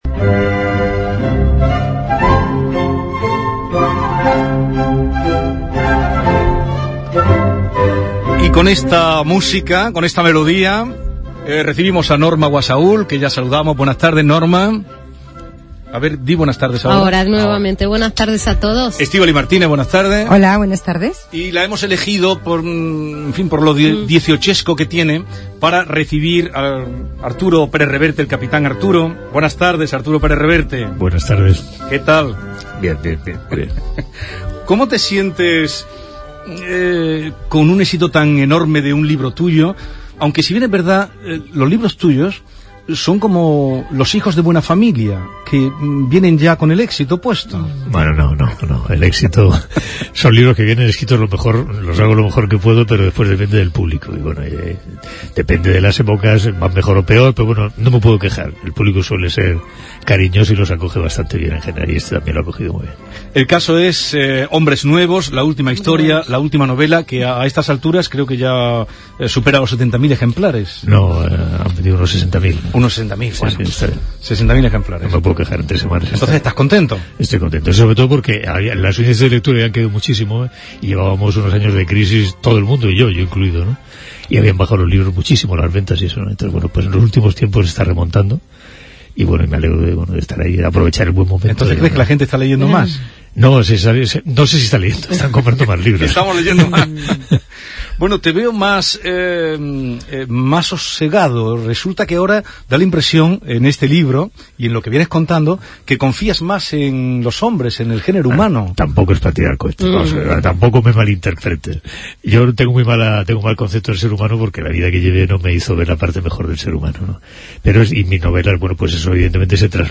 ENTREVISTAS APARECIDAS EN T.V. y RADIO sobre "HOMBRES BUENOS"